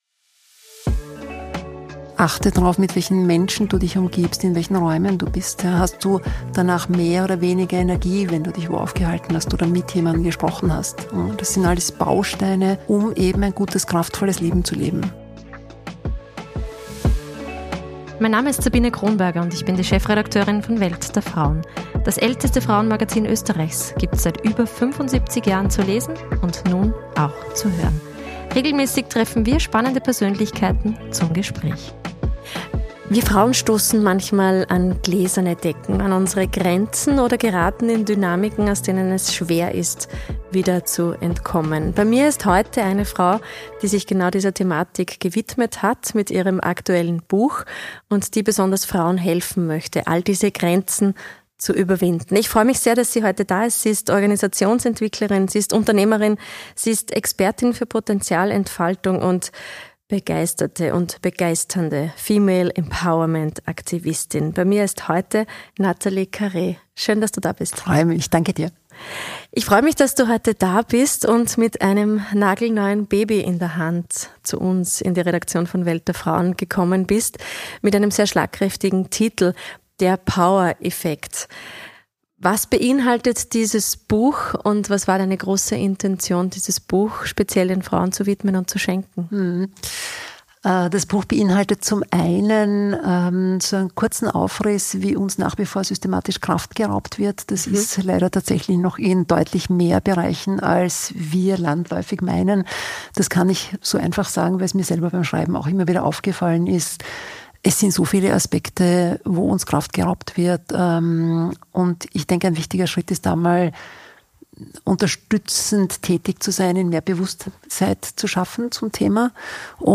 Ein Gespräch über das Miteinander in unserer Gesellschaft, Sisterhood unter Frauen und das ehrliche Interesse an Menschen, um sie zu ihrem Vorteil zu vernetzen.